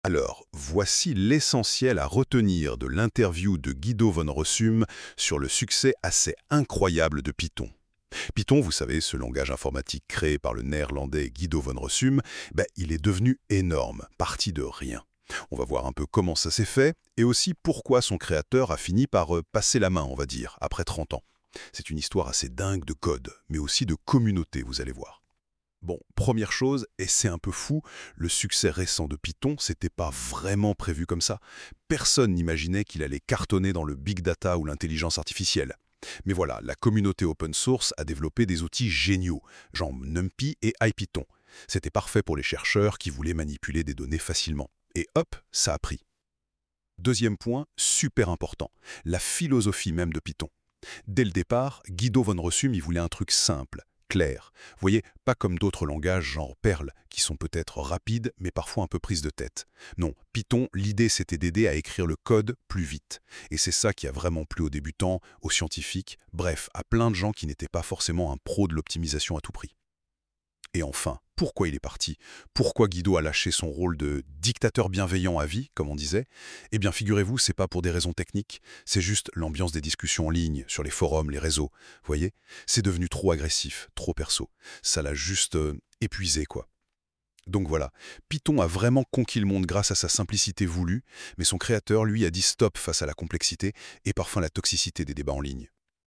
Guido van Rossum , le créateur du langage de programmation Python , discutant du succès inattendu et de la croissance exponentielle du langage depuis le début des années 2010. Van Rossum explique que le succès récent de Python est largement dû à son adoption dans les domaines du big data et de l’intelligence artificielle , des domaines qui n’existaient pas sous leur forme actuelle lors de la création du langage.
L’entretien aborde aussi la décision de Van Rossum de prendre sa retraite et de se retirer de la direction du projet en raison du stress et du ton désagréable pris par les discussions en ligne de la communauté. image 300×168 2.46 KB Python : Les secrets contre-intuitifs de son succès, révélés par son créateur Python est partout.